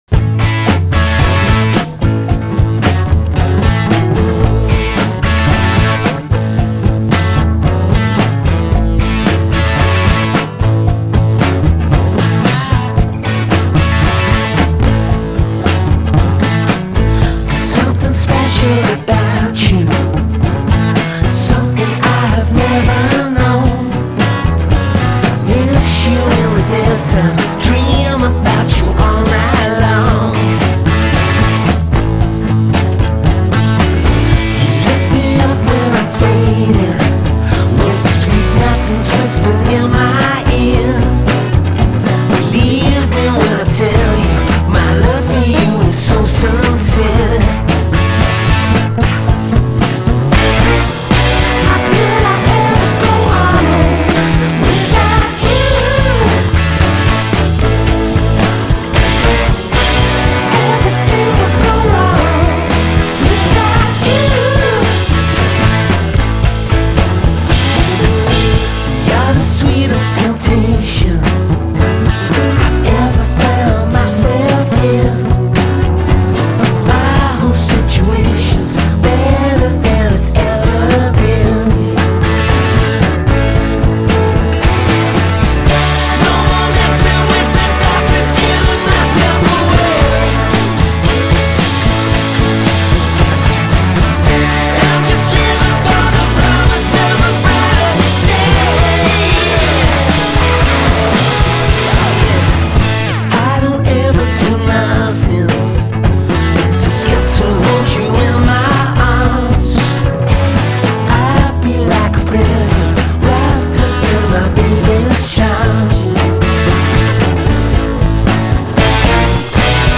Альбом - в стиле психо-рок-н-ролл.